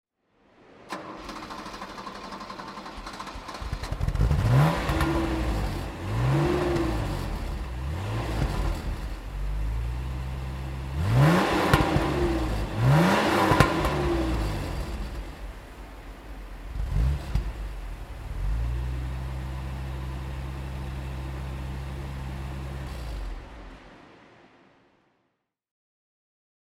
With its 2.8-liter V6 engine and 150 hp, it was also a sporty match for the competition, but without being able to replace the Capri, as Ford had probably originally planned. This driving report portrays an early Sierra XR4i in current and historical pictures, in the sales brochure and acoustically.
Ford Sierra XR4i (1983) - Starten und Leerlauf
Ford_Sierra_XR4i_1983.mp3